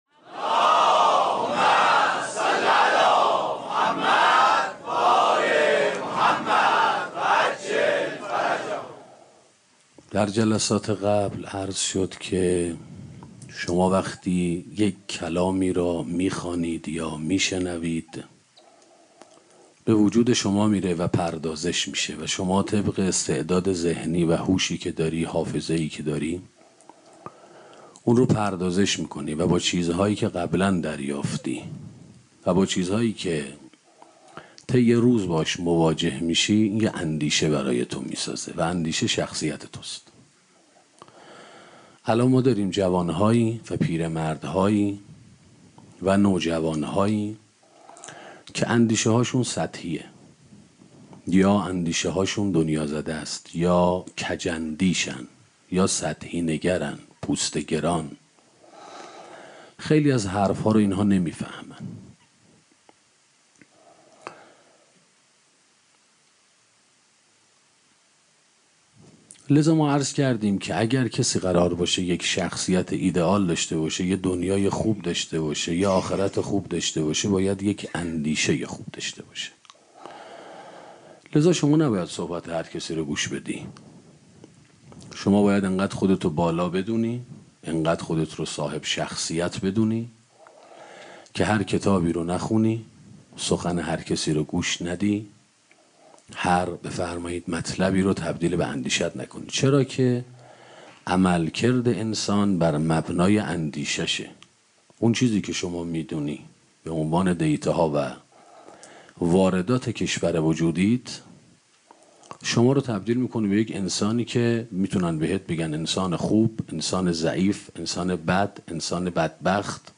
سخنرانی اندیشه و انگیزه 3 - موسسه مودت